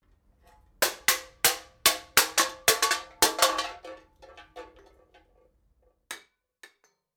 selected-coke-can.mp3